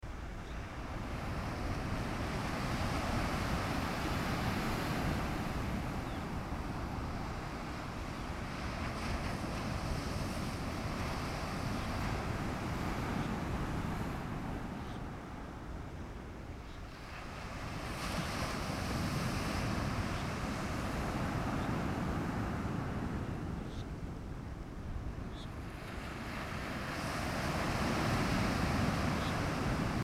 新城老街往海邊走去，即可抵達新城海堤。相較於比較著名的海灘景點，鮮少人會來到新城海堤，因此，可以的獨自在這裡欣賞180度的蔚藍海岸，靜靜的聽著海浪拍打的聲音。